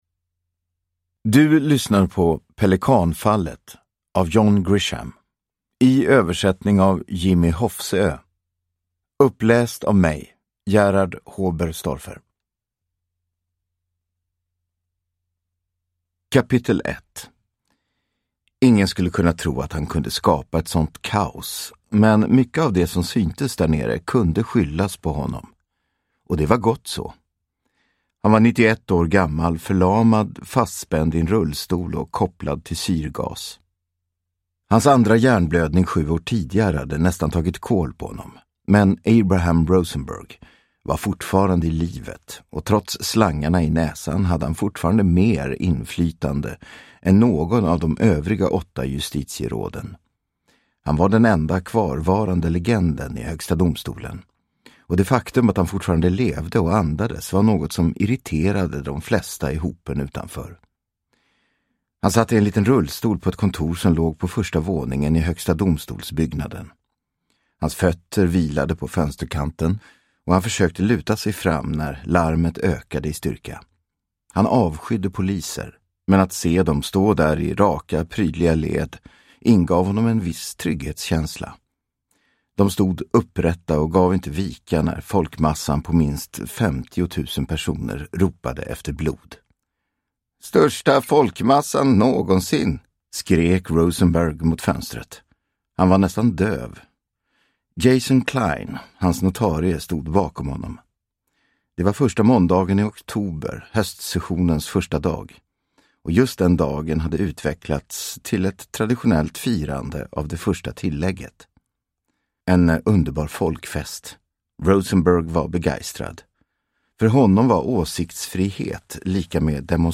Pelikanfallet – Ljudbok – Laddas ner